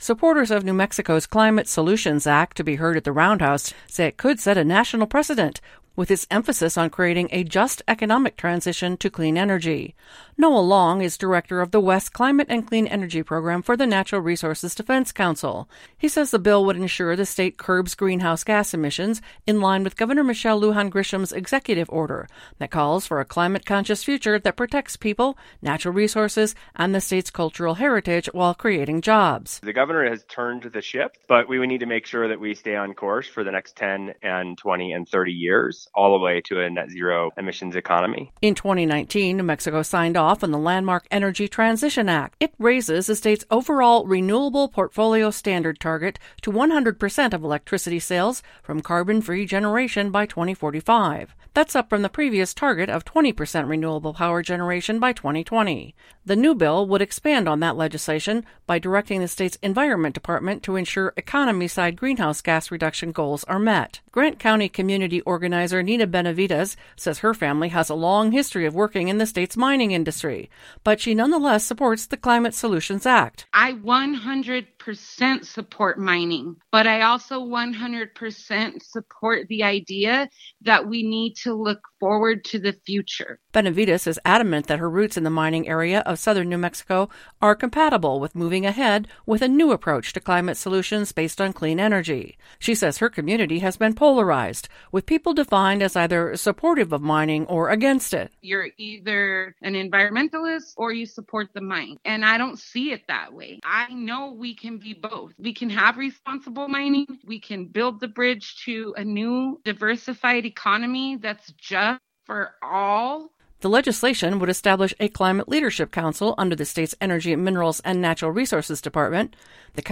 THE FOLLOWING RADIO REPORTS ARE DONE IN PARTNERSHIP WITH PUBLIC NEWS SERVICE.